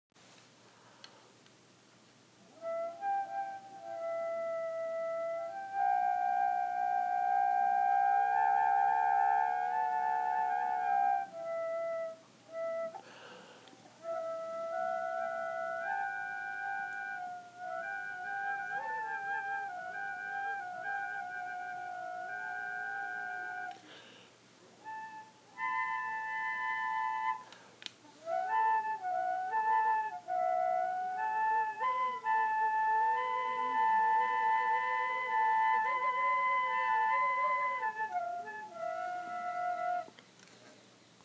4 tin whistles
Impr Flageolets 4wh
Multiphonics on two instruments simultaneously